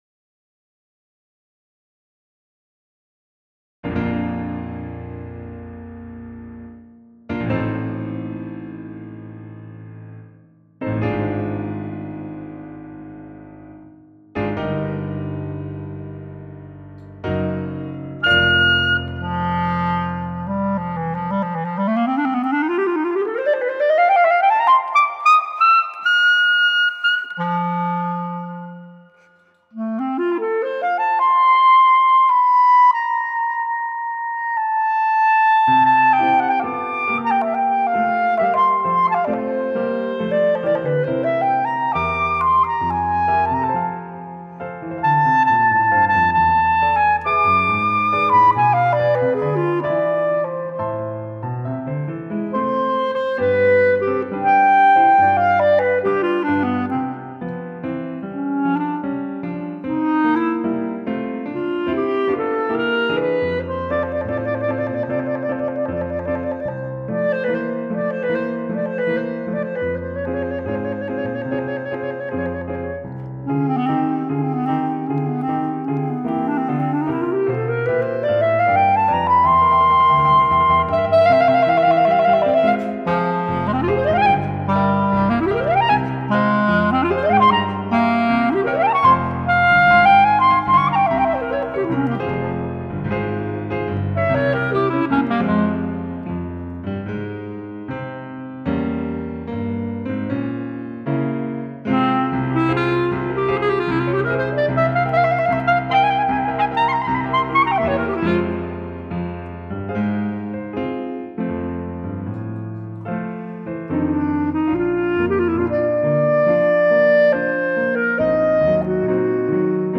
for Bb Clarinet & Piano